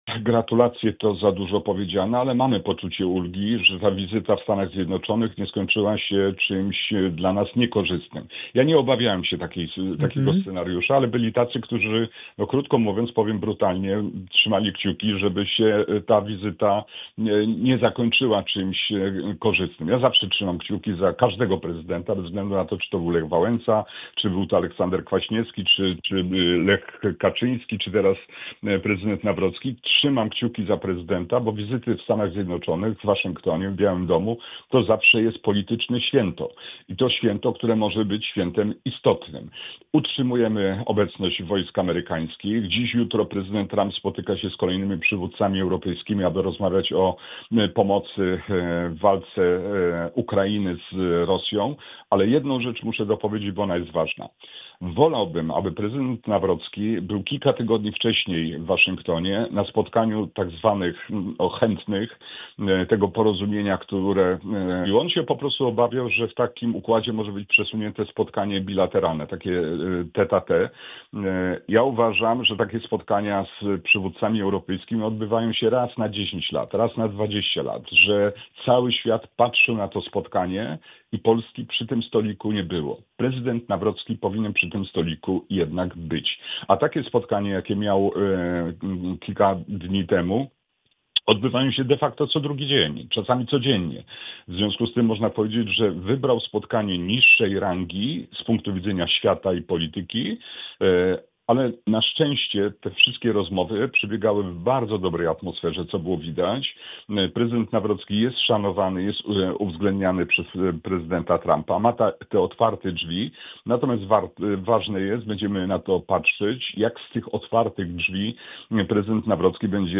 W rozmowie „Poranny Gość” z europosłem Bogdanem Zdrojewskim rozmawialiśmy o wizycie prezydenta Karola Nawrockiego w USA, relacjach z rządem, a także o zmianach i wyborach w PO.